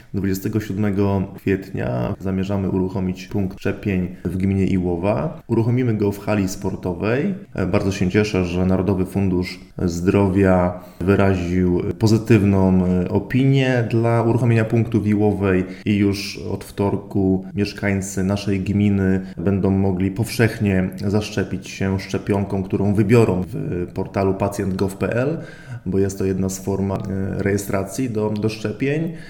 Jak mówi burmistrz Iłowej Paweł Lichtański, jeśli mieszkańcy i gminy mają zaplanowane szczepienia daleko od miejsc zamieszkania, można to zmienić i wybrać iłowski punkt: